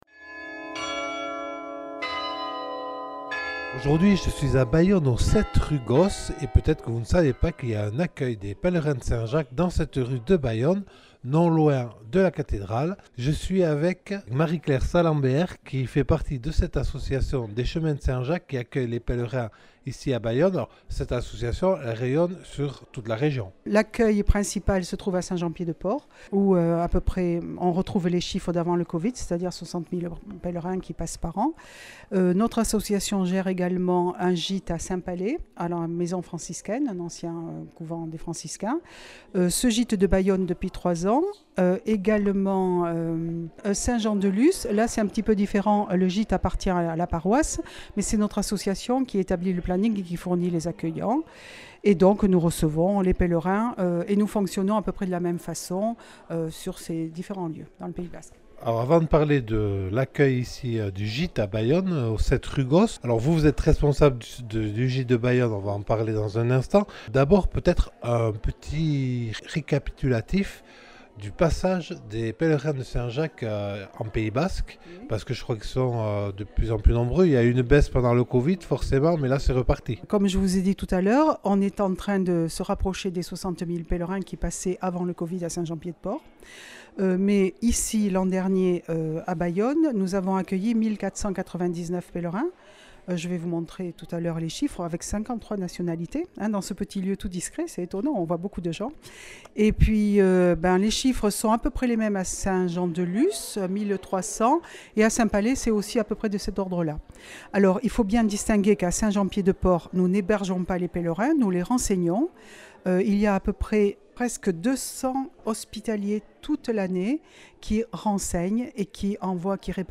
Témoignage de deux pèlerins.